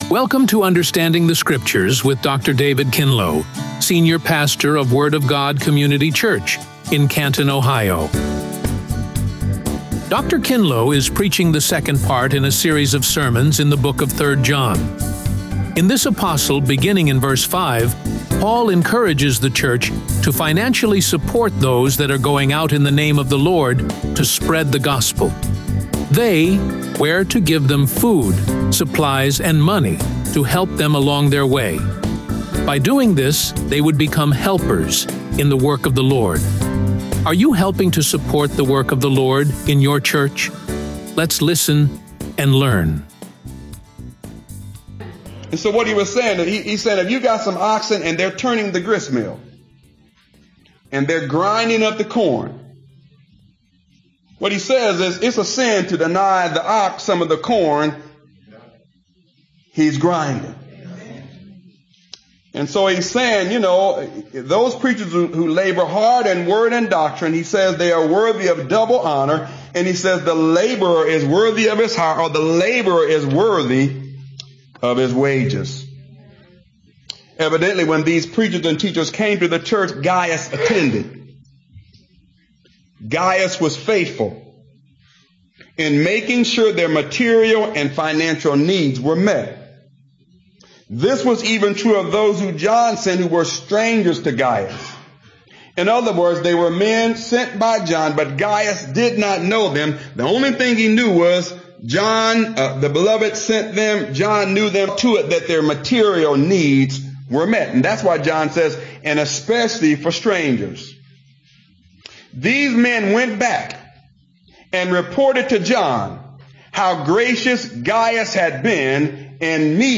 RADIO SERMON